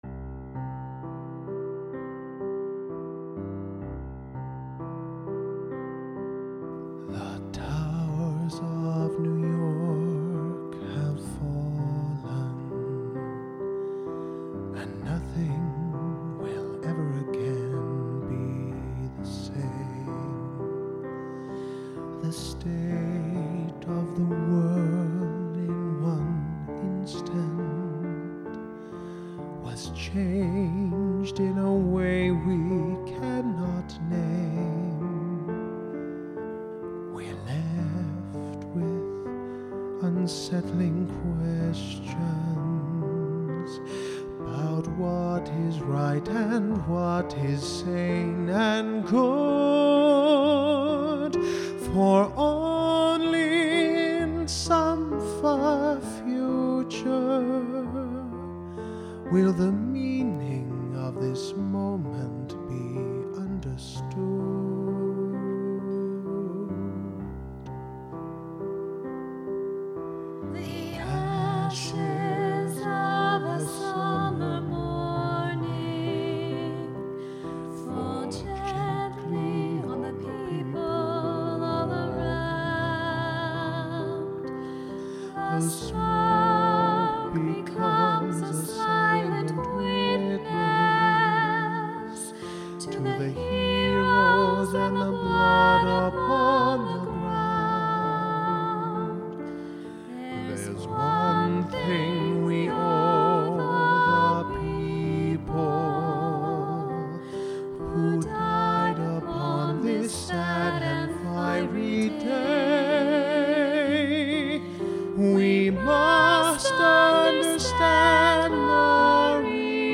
Hymn to New York (Vocals